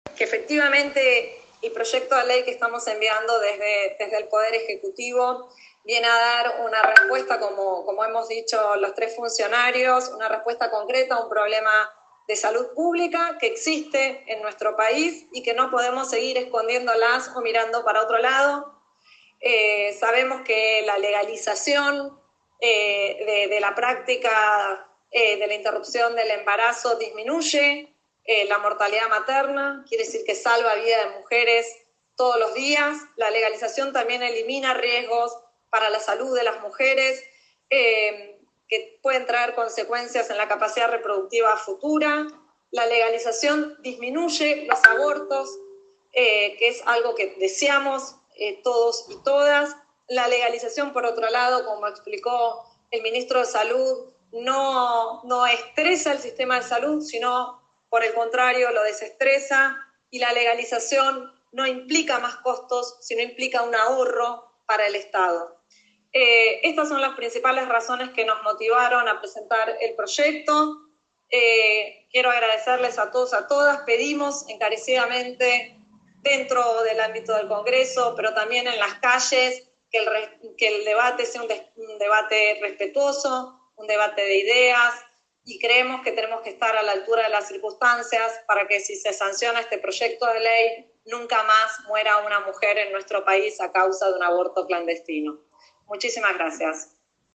Fragmento de la exposición de Elizabeth Gómez Alcorta en la Cámara de Diputados y Diputadas sobre el proyecto de Interrupción Voluntaria del Embarazo.